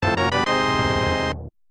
item-get-1.mp3